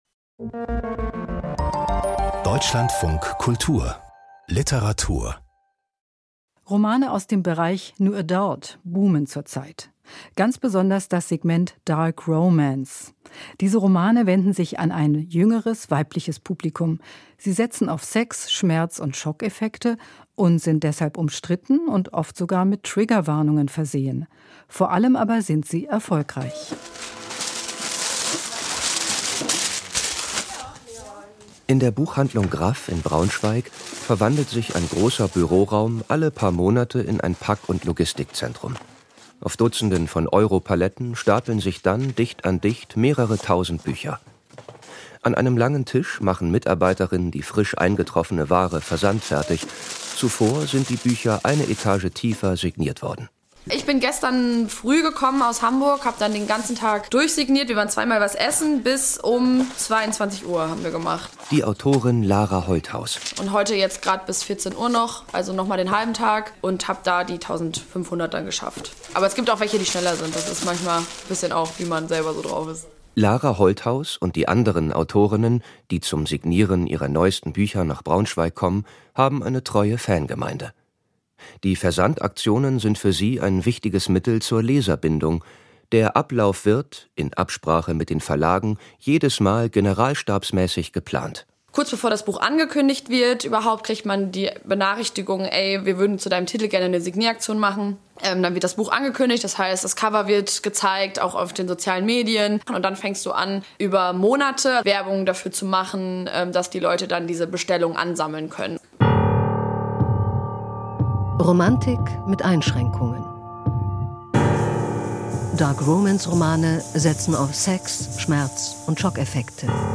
Literarische Features und Diskussionen.